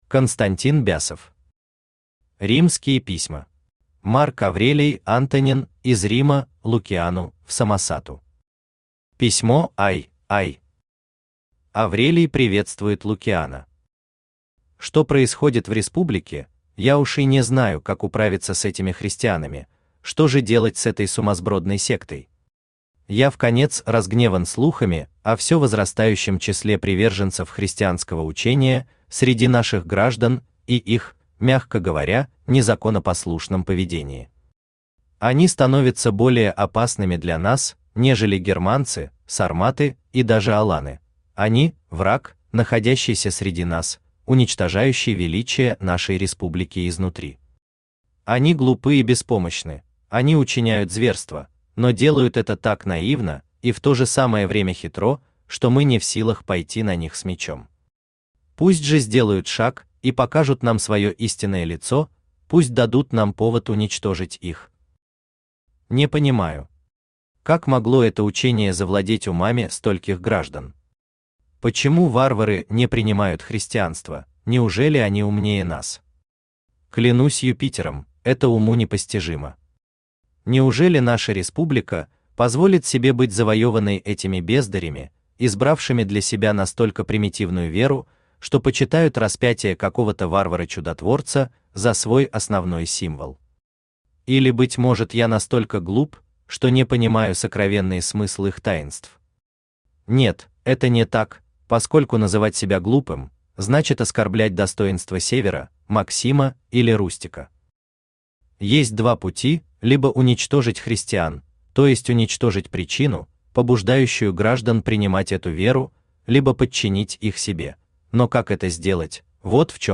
Aудиокнига Римские письма Автор Константин Бясов Читает аудиокнигу Авточтец ЛитРес.